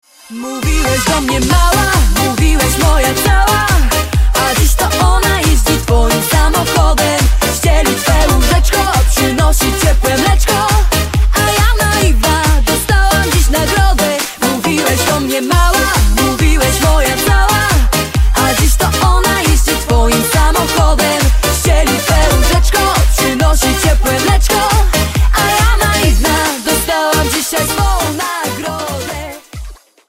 Disco polo